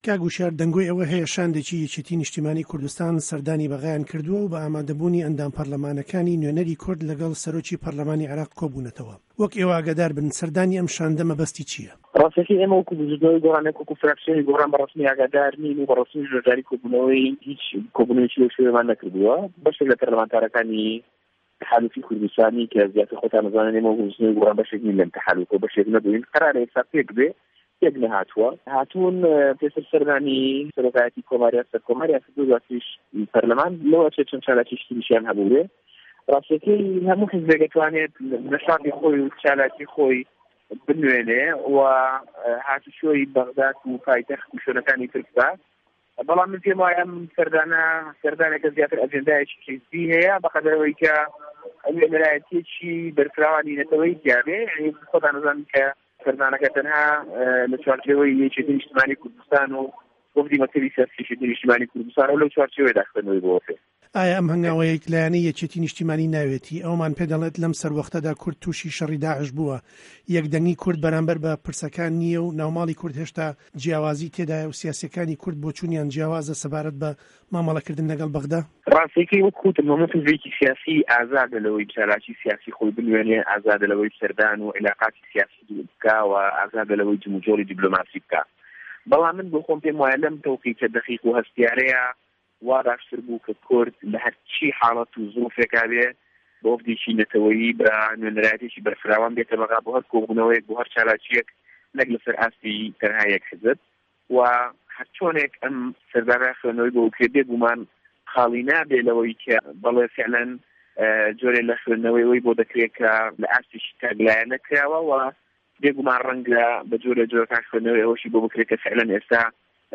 عێراق - گفتوگۆکان
له‌مگفتووگۆیه‌دا له‌گه‌ڵ به‌شی کوردی ده‌نگی ئه‌مریکا سه‌رۆکی فراکسێۆنی گۆران له‌په‌رله‌مانی عێراق هووشیار عه‌بدوڵڵا ده‌ڵێت: ده‌بێت کورد بۆ دانوستاندن له‌گه‌ڵ به‌غدا به‌شاندێکی به‌فراوانی لایه‌نه‌کان یاخوود له‌ئاستی حکومه‌ت یان په‌رله‌مان به‌شداربێت.